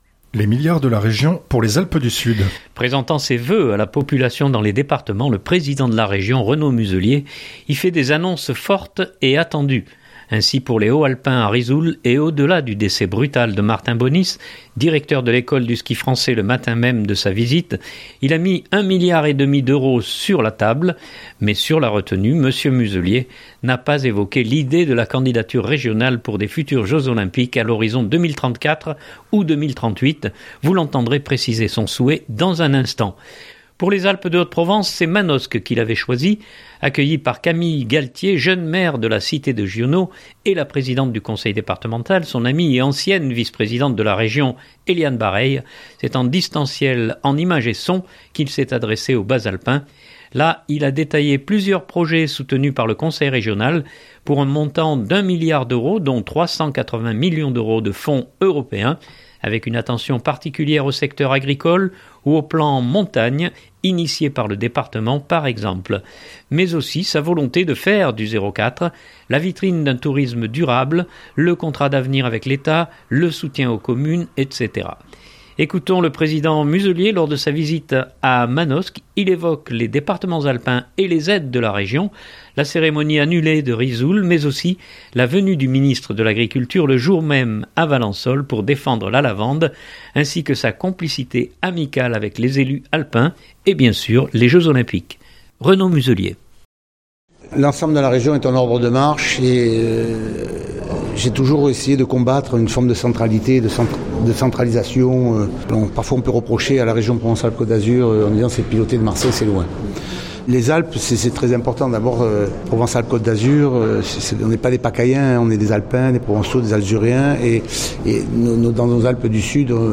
Mais aussi sa volonté de faire du 04 la vitrine d’un tourisme durable, le contrat d’avenir avec l’Etat, le soutien aux communes etc. Ecoutons le président Muselier lors de sa visite à Manosque, il évoque les départements alpins et les aides de la Région, la cérémonie annulée de Risoul, mais aussi la venue du ministre de l’agriculture le jour même à Valensole pour défendre la lavande ainsi que sa complicité amicale avec les élus alpins et bien sûr les Jeux Olympiques.